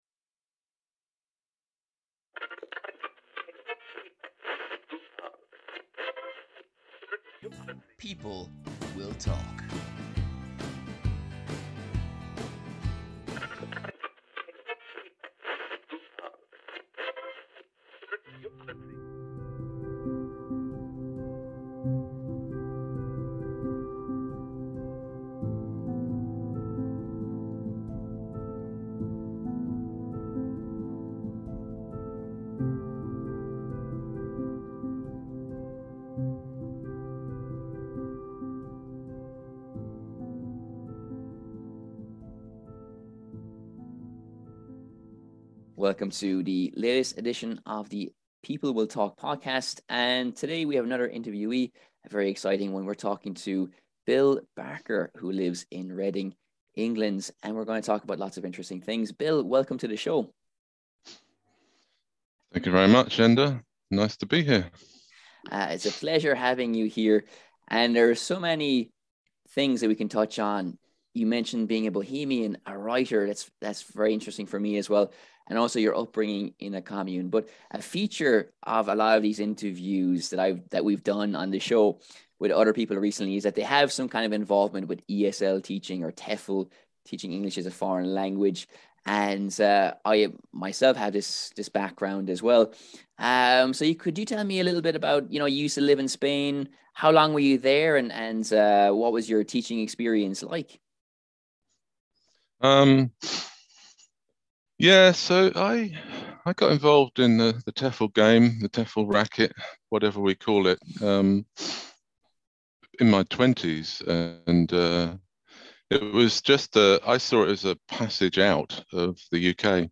A sit-down with a bohemian writer who is also an ex-ESL teacher and a former hippy commune resident.